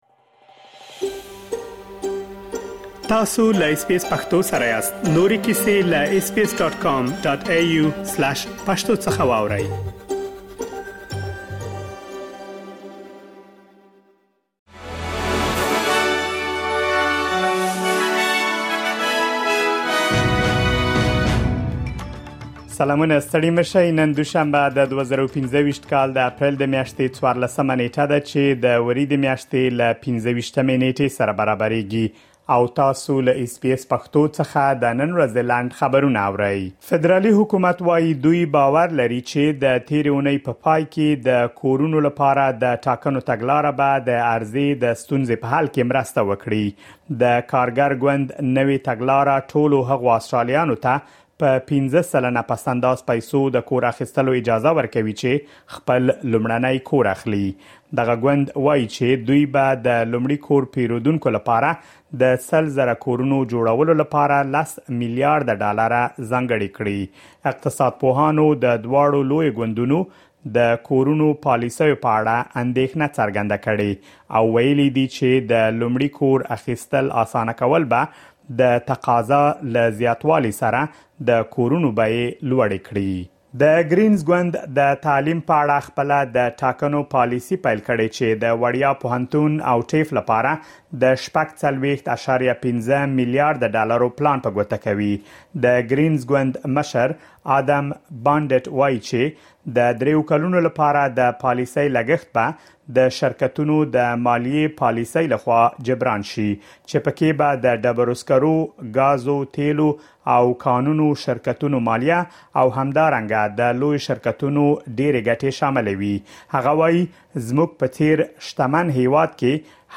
د اس بي اس پښتو د نن ورځې لنډ خبرونه | ۱۴ اپریل ۲۰۲۵
د اس بي اس پښتو د نن ورځې لنډ خبرونه دلته واورئ.